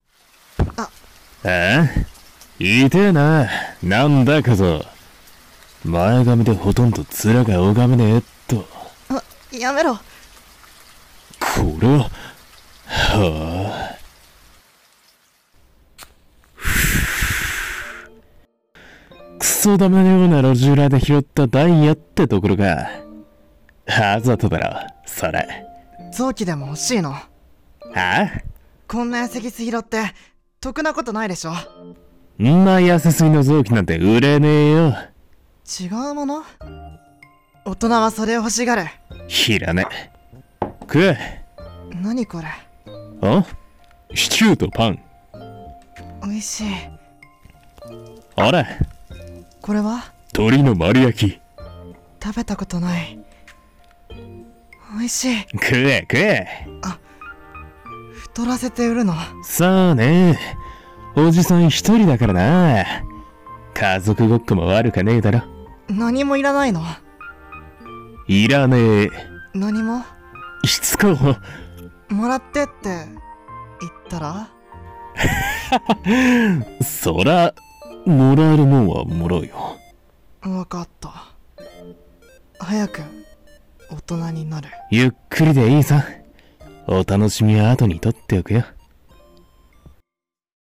最高のフルコースを 【 2人声劇